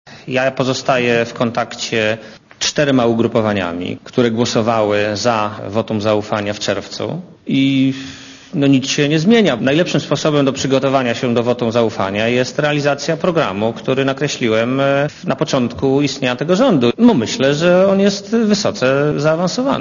* Mówi premier Marek Belka*